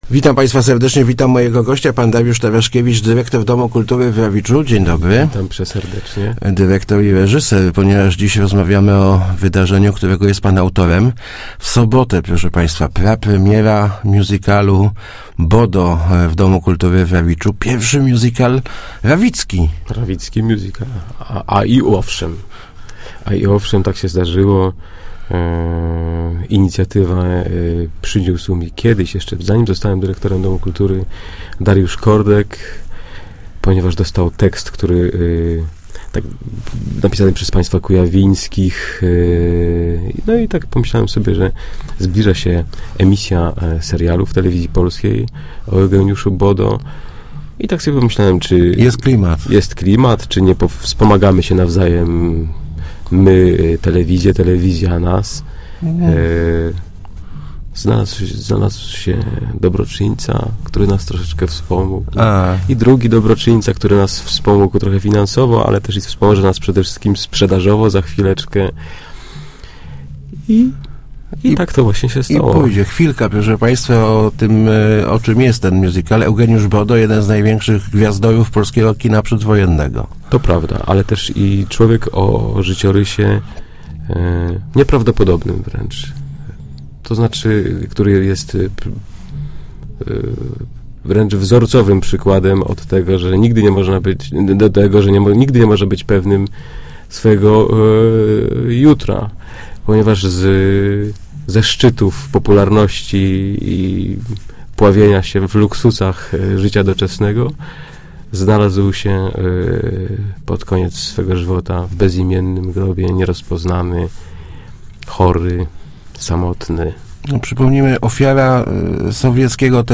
Start arrow Rozmowy Elki arrow Musical w Rawiczu